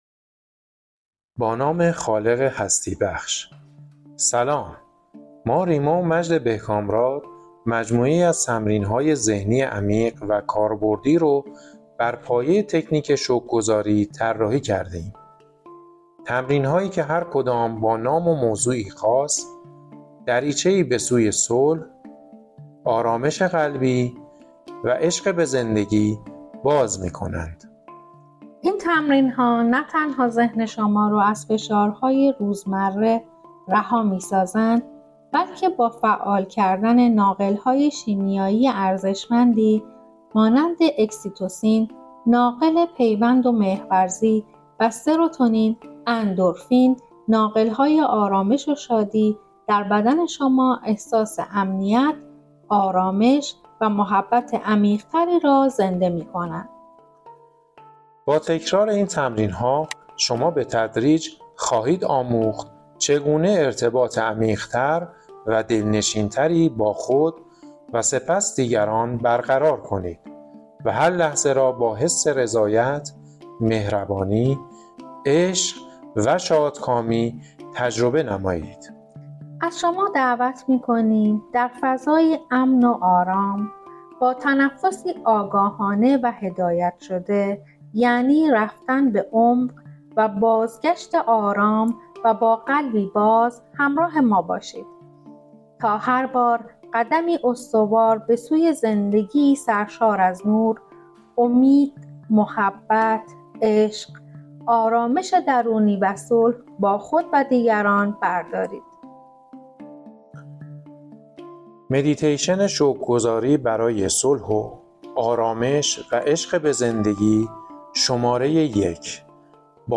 این مدیتیشن‌ها با ترکیبی از موسیقی، تکنیک‌های تن‌آرامی و جملات شکرگزاری، نه تنها ذهن را از فشارها رها می‌کنند بلکه هورمون‌های شادی و آرامش را در بدن فعال می‌سازند.